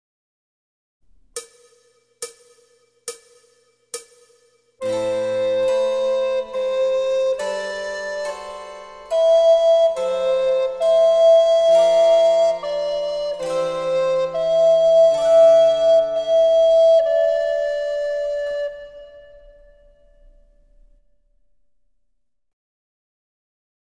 リコーダー演奏